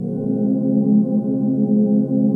ATMOPAD07 -LR.wav